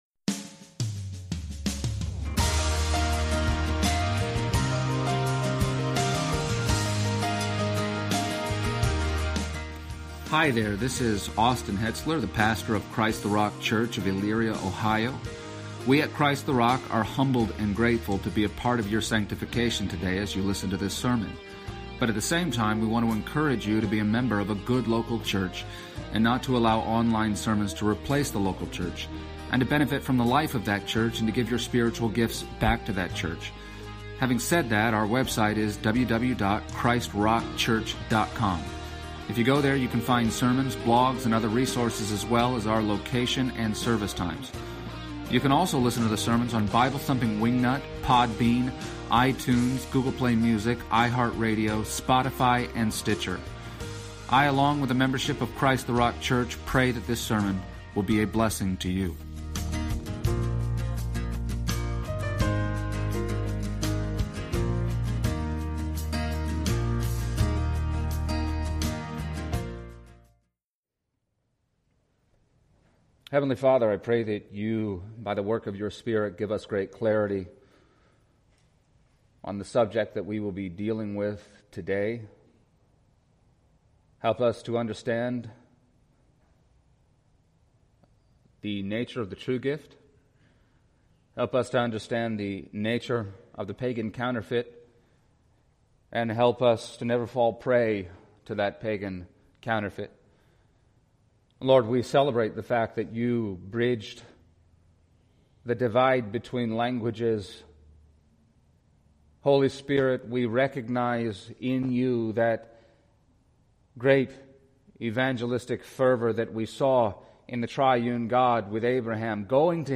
Passage: Acts 2:1-13 Service Type: Sunday Morning